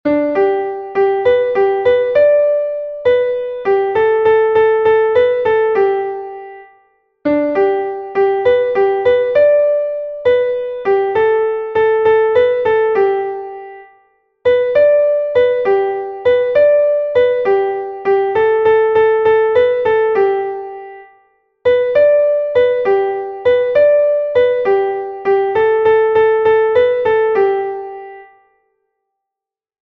Einstimmige Melodie im Violinschlüssel, G-Dur, 6/8-Takt, mit der 1. Strophe des Liedtextes.
ich-geh-mit-meiner-laterne_klavier_melodiemeister.mp3